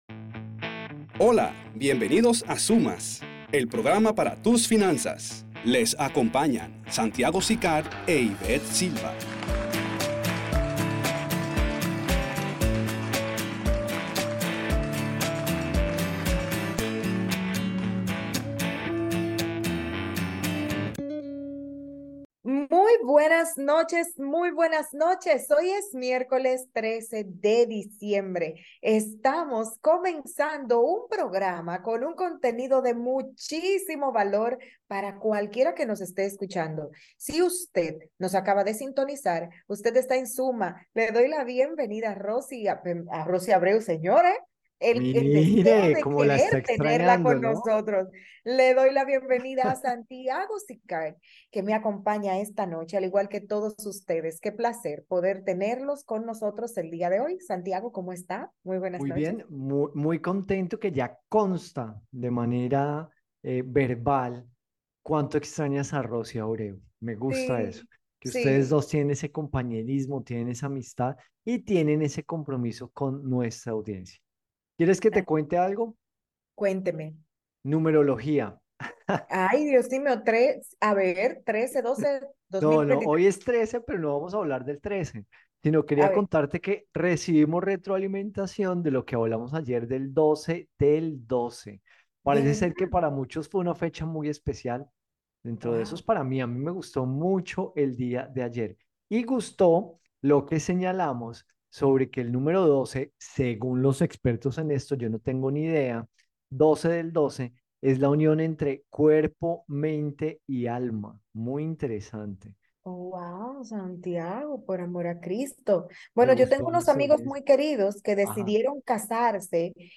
Escucha todo nuestro porgrama de radio de hoy.